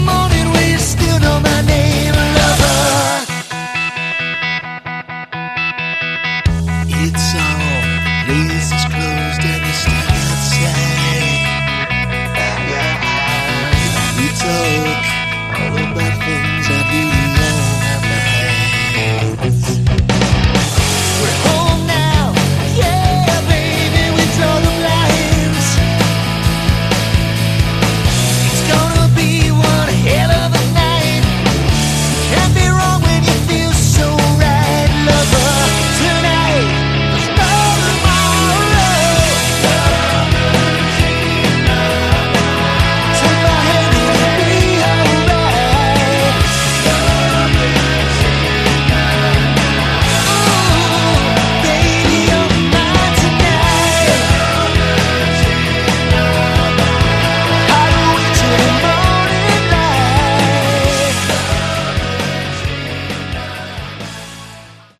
Category: Melodic Rock/Aor
lead vocals
guitars, bass, vocals
drums, keys, vocals